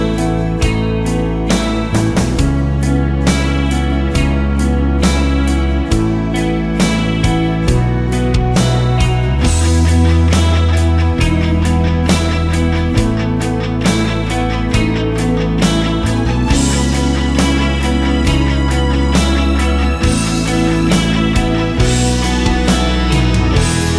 no Backing Vocals Rock 4:14 Buy £1.50